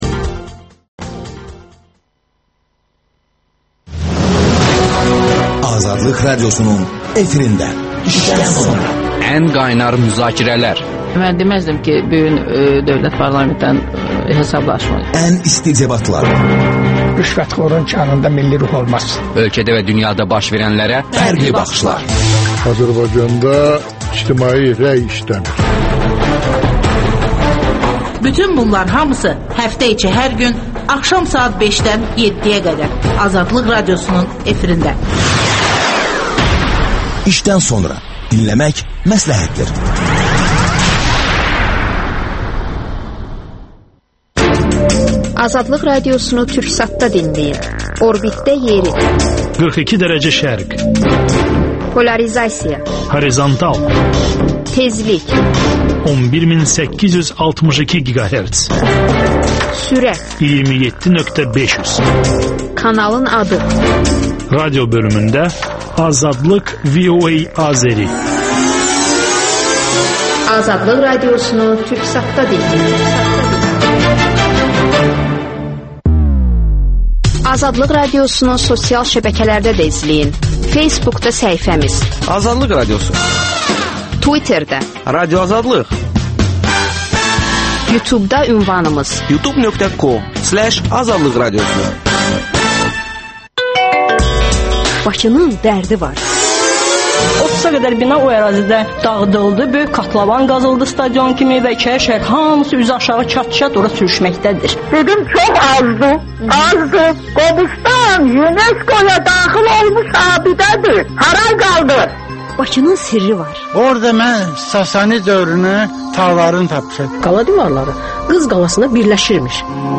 Müstəqillik elan olunan dövrdə Ali Sovetin deputatı olmuş Sülhəddin Əkbərlə müsahibə.